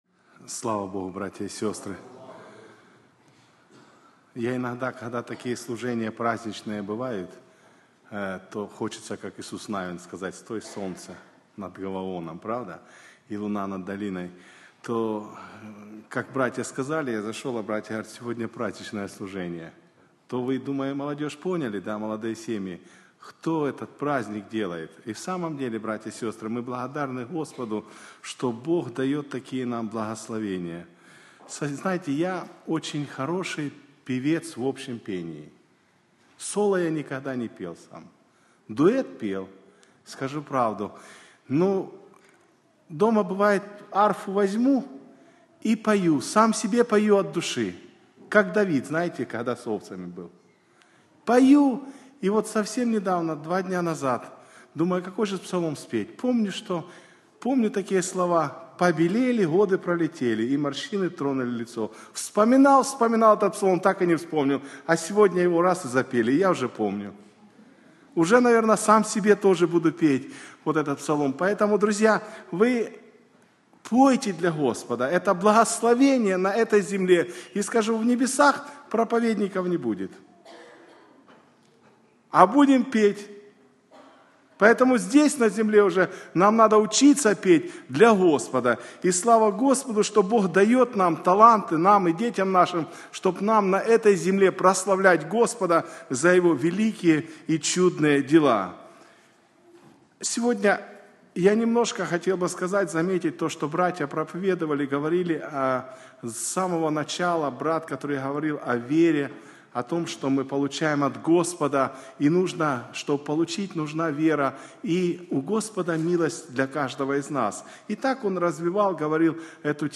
11+Preacher+5.mp3